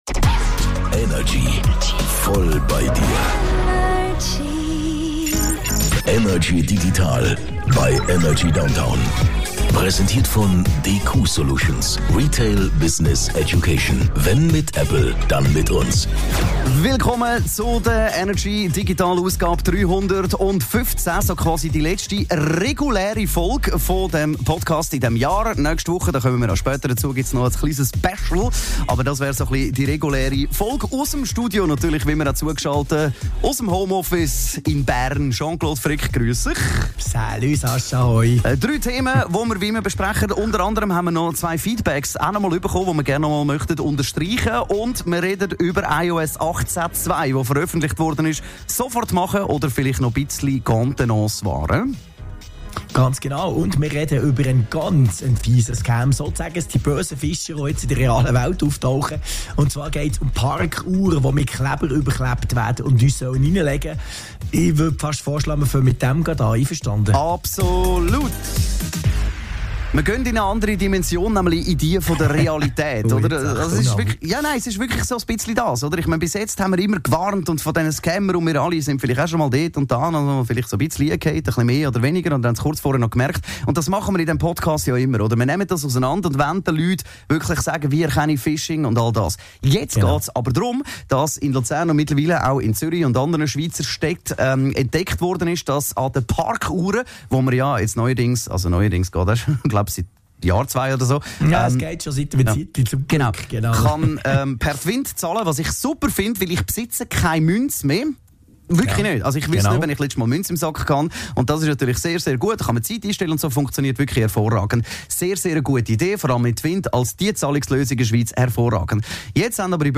aus dem HomeOffice über die digitalen Themen der Woche .